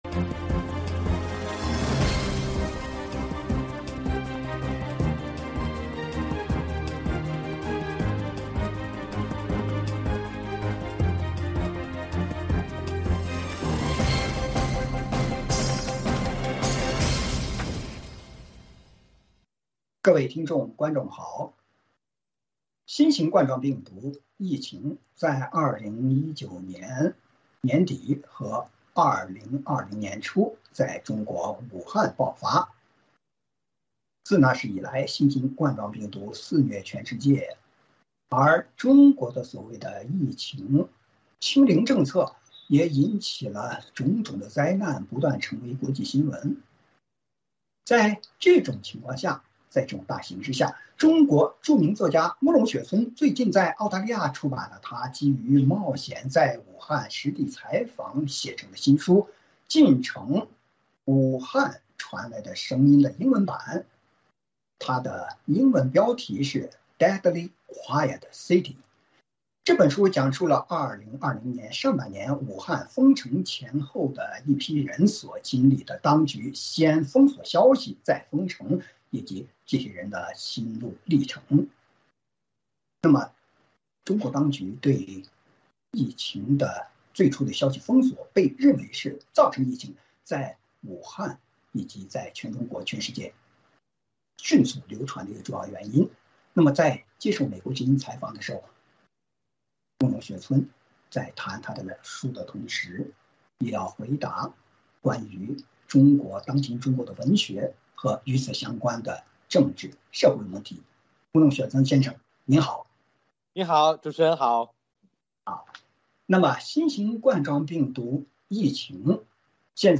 时事大家谈：专访《禁城：武汉传来的声音》作者慕容雪村：“希望向全世界讲述中国人活在一个什么样的制度之下”